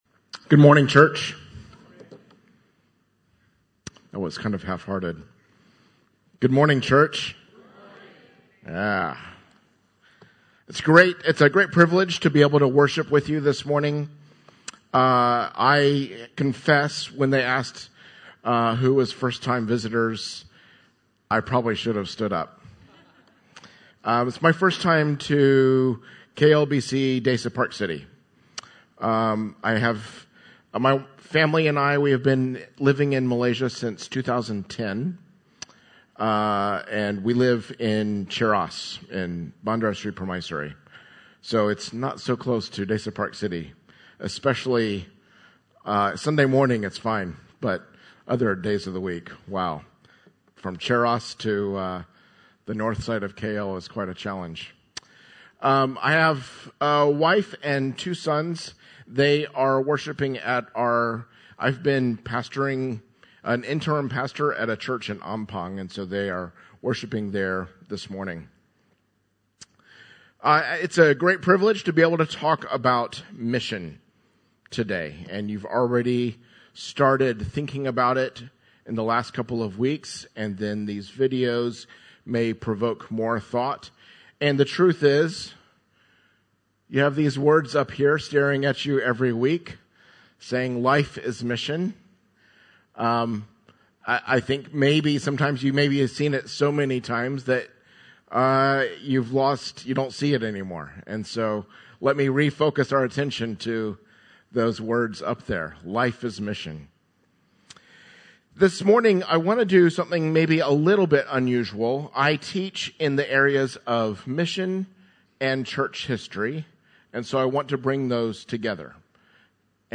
Passage: Isaiah 54 Service Type: Sunday Service (Desa ParkCity) « Everyone